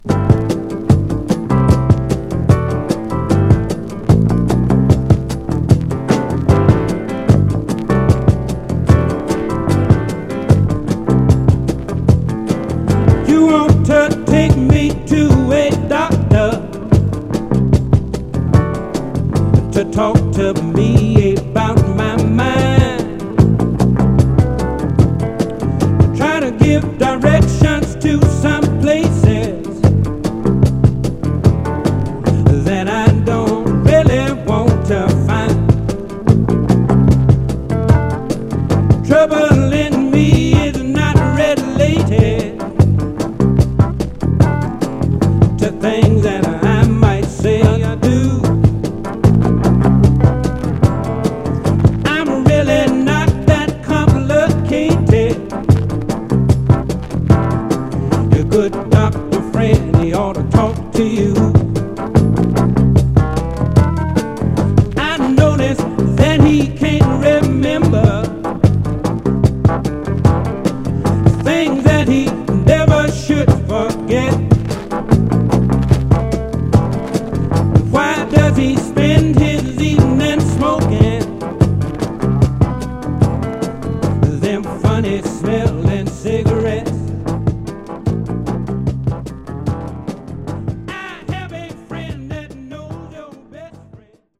小気味良いビートで聴かせる
ただしプレス起因によるバックグラウンド・ノイズあり。
※試聴音源は実際にお送りする商品から録音したものです※